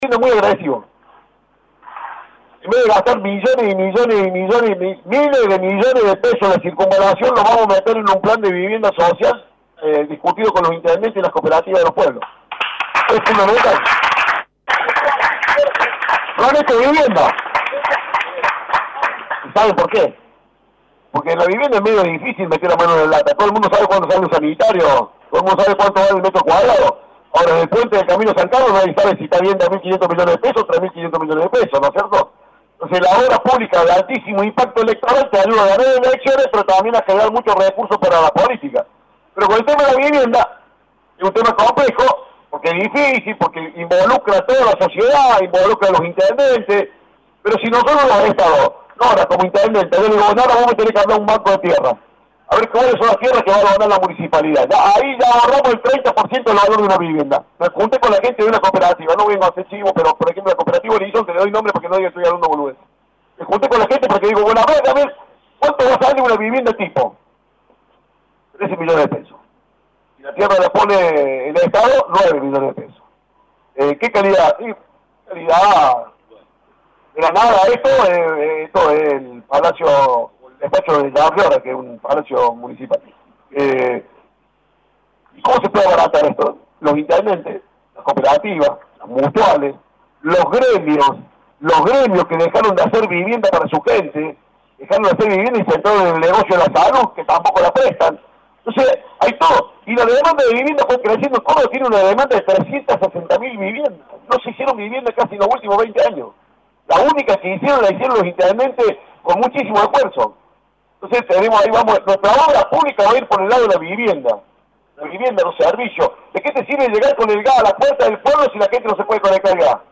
El candidato a gobernador de Juntos por el Cambio Luis Juez visitó la localidad de Porteña y luego de recorrer algunos comercios e instituciones, dialogó con los medios de prensa.
Consultado por LA RADIO 102.9 FM sobre los principales planteos de los vecinos de Porteña afirmó que las principales inquietudes fueron sobre seguridad y jubilados.